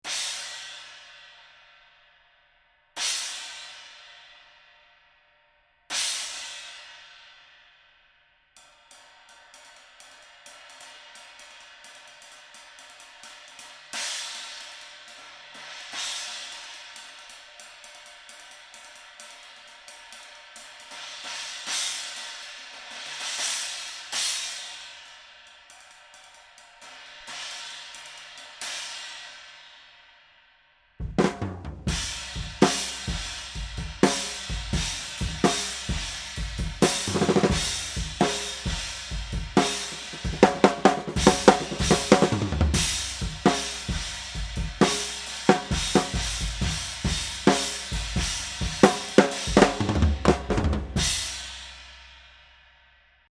16 Turk Effects crash 18 hole 926g